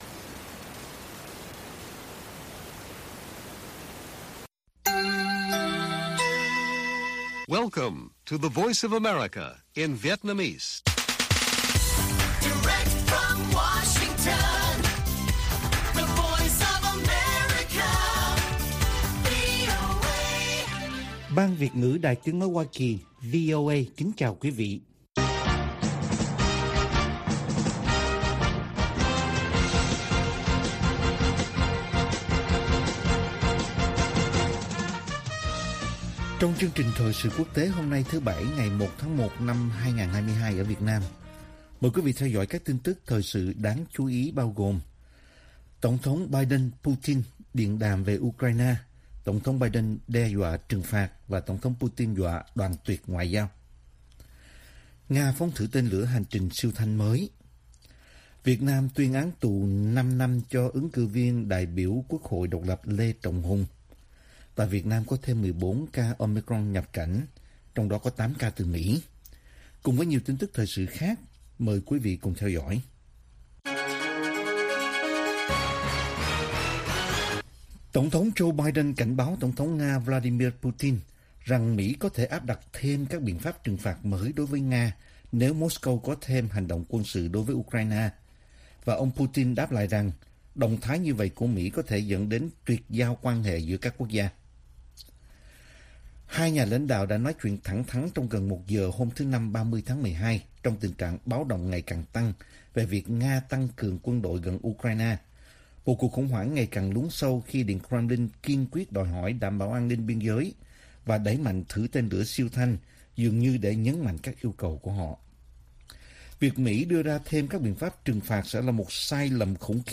Bản tin VOA ngày 1/1/2022